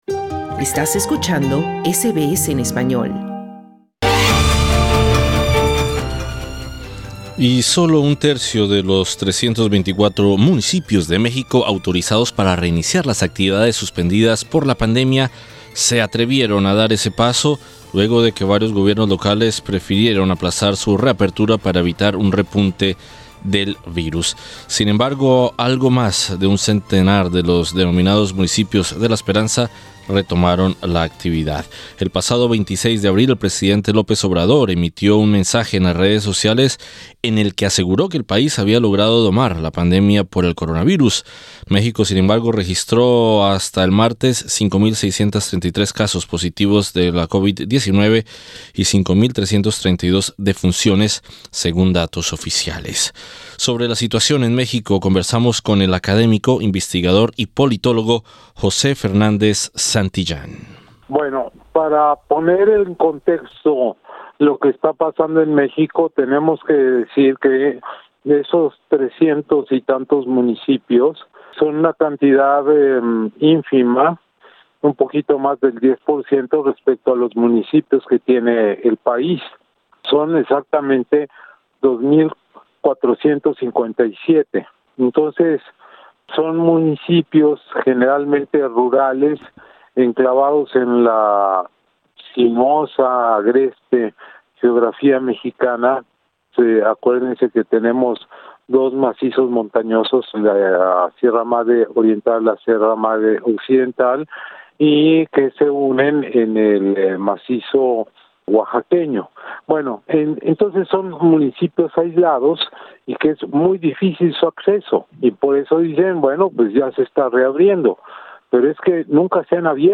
Sobre la situación en México, conversamos con el académico, investigador y politólogo